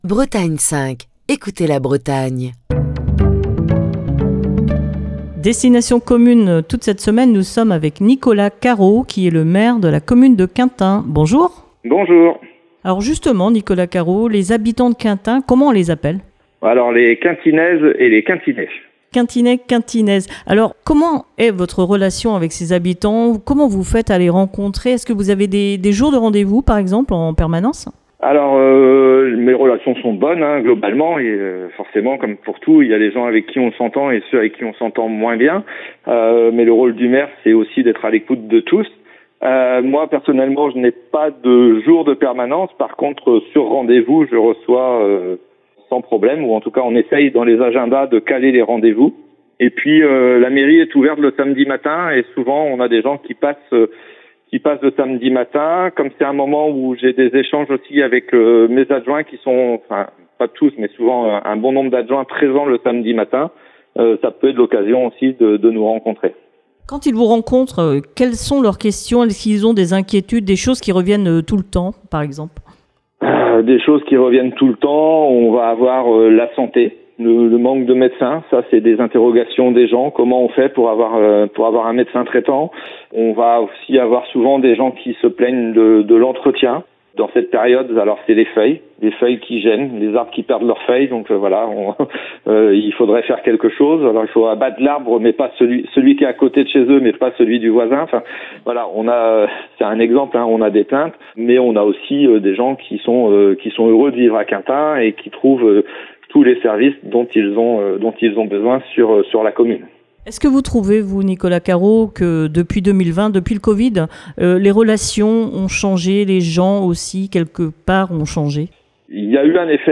est au téléphone avec le maire de Quintin, Nicolas Carro, qui vous propose de partir à la découverte de sa ville et de partager son quotidien d'élu.